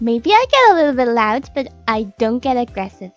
Worms speechbanks
Boring.wav